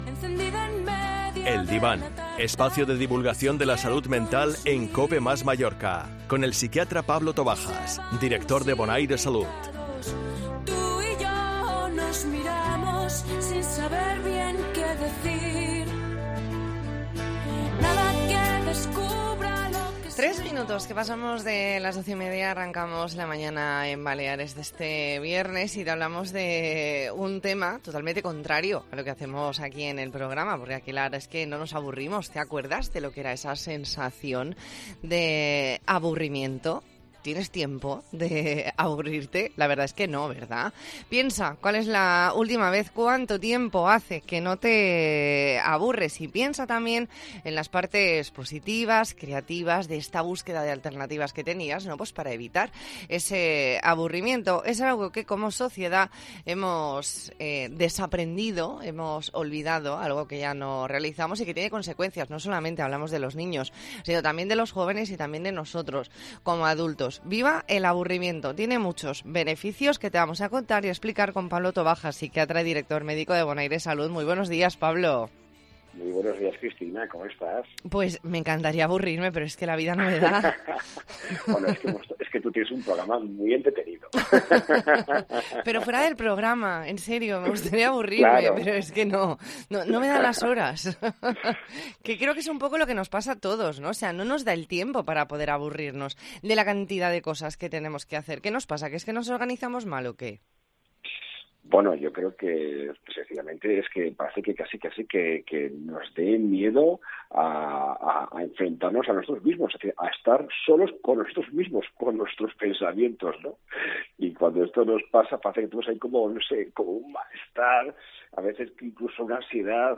Entrevista en La Mañana en COPE Más Mallorca, viernes 30 de septiembre de 2022.